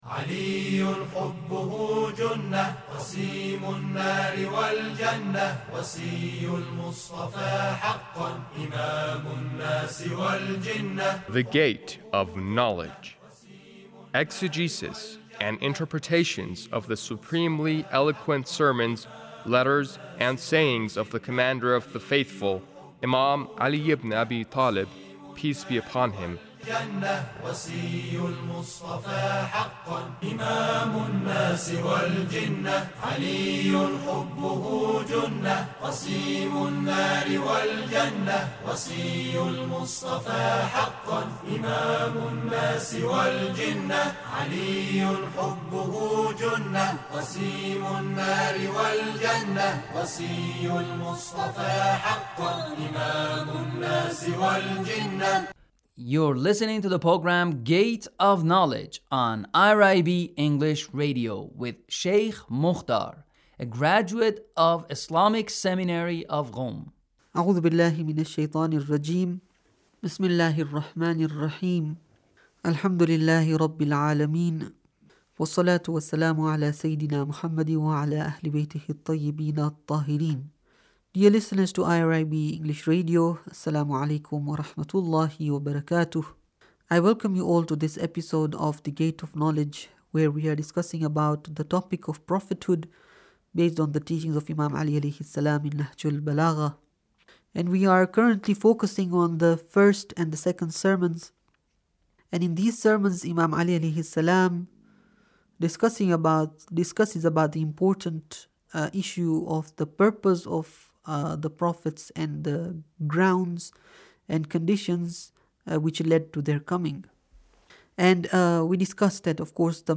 Sermon 2 - Prophethood 34